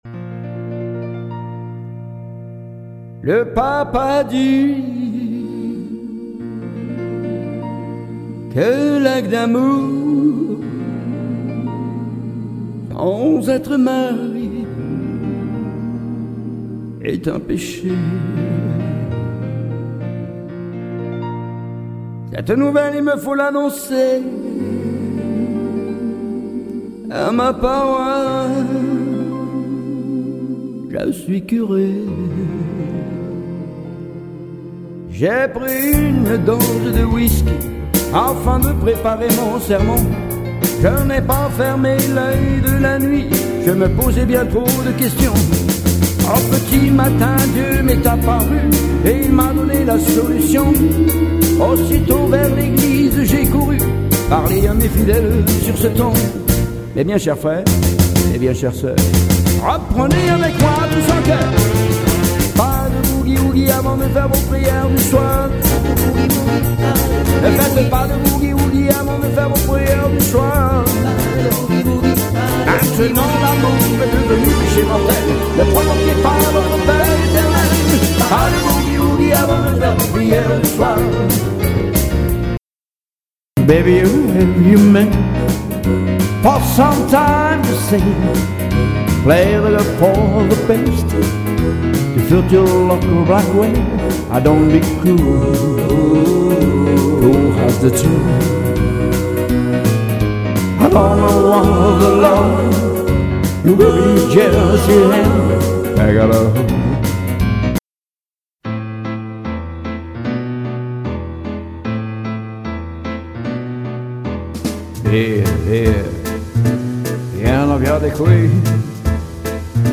Claviériste chanteur animateur, variétés international, tout style de danse, thé dansant, piano-bar, soirées privés.animation assurées.